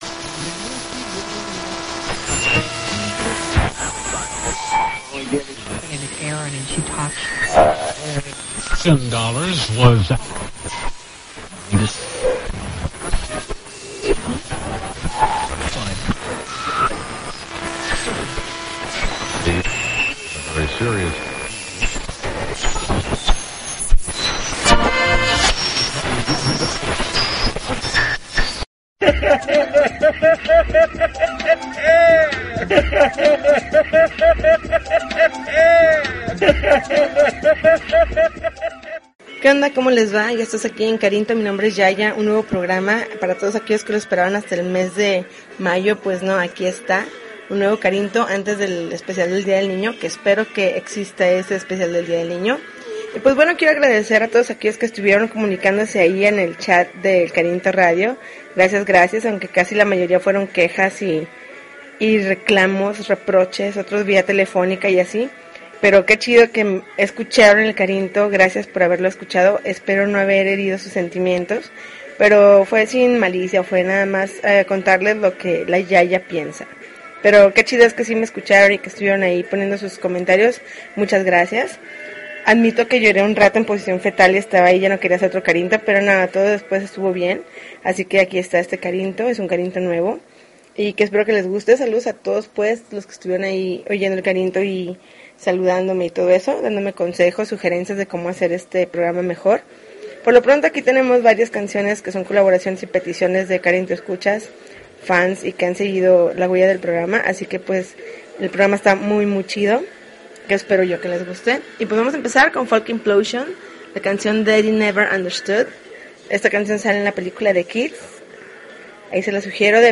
April 11, 2013Podcast, Punk Rock Alternativo